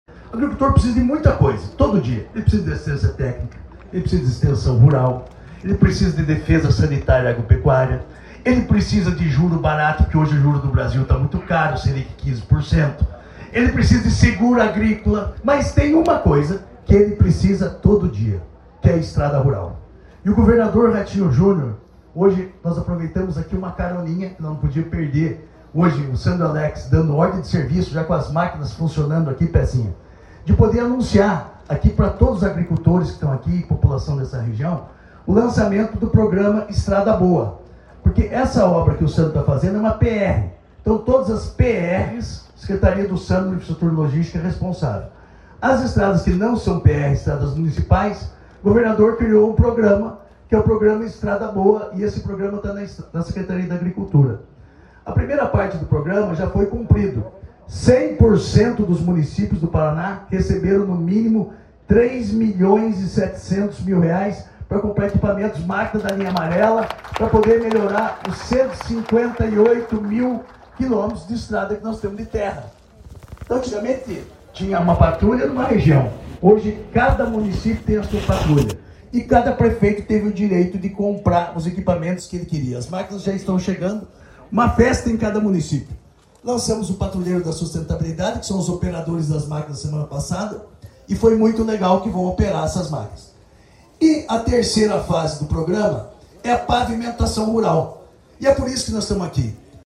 Sonora do secretário da a Agricultura e do Abastecimento, Marcio Nunes, sobre a liberação de R$ 60 milhões para pavimentação de estradas rurais na região Oeste